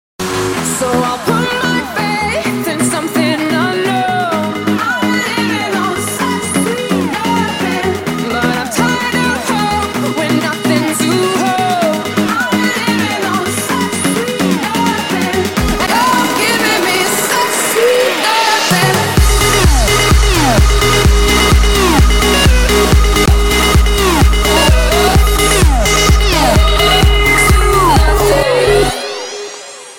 calm-love melody hook
loud clean HD audio caller tone